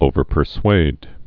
(ōvər-pər-swād)